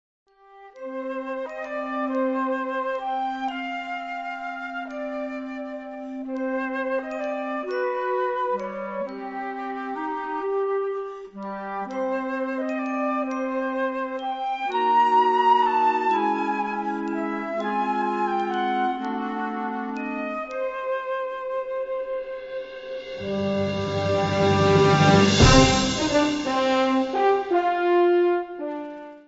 Gattung: Medley zum Film
Besetzung: Blasorchester